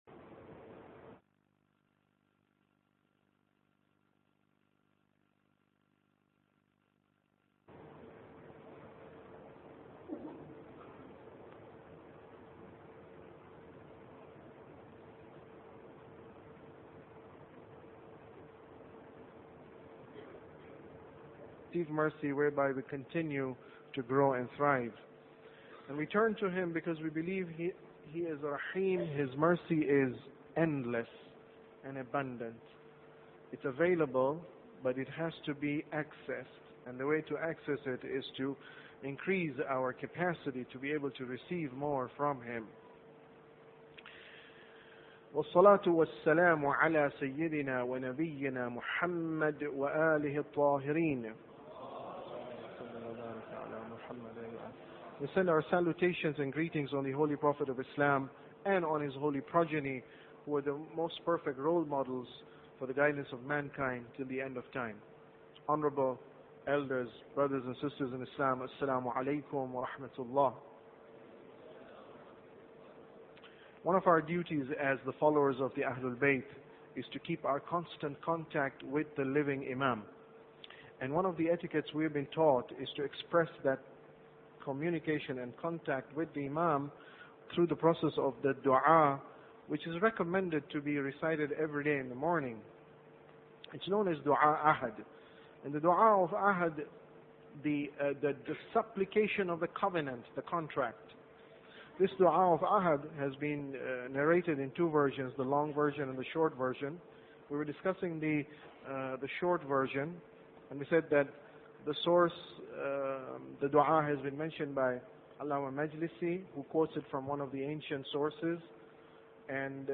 Lecture dua explanation - 30 mts